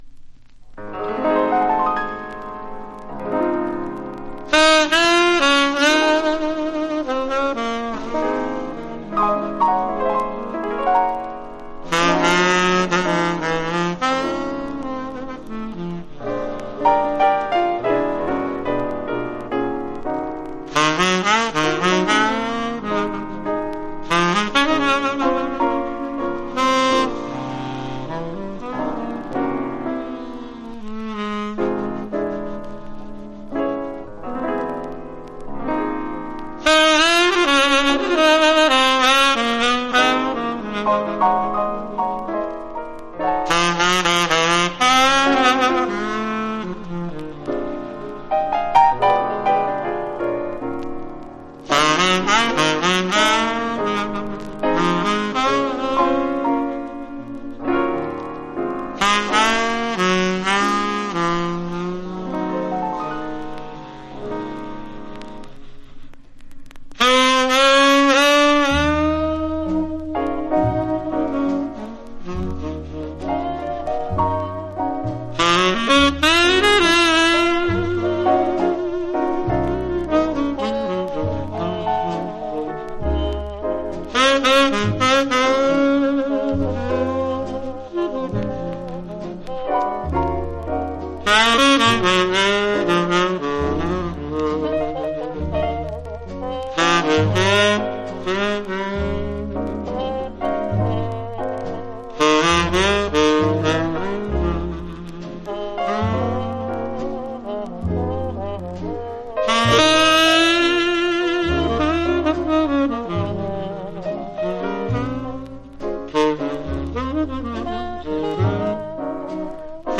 （小傷によりチリ、プチ音ある曲あり…